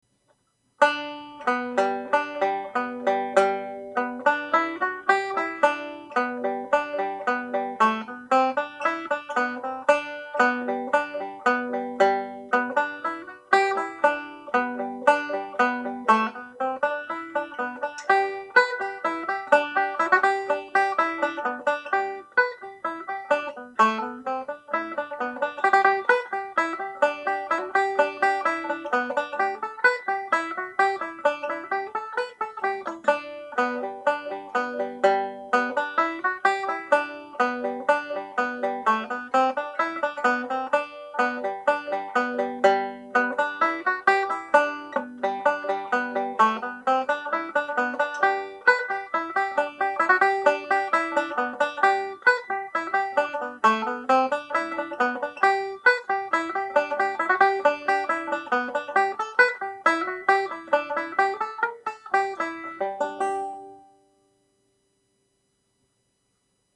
Reel (G Major)
played at normal speed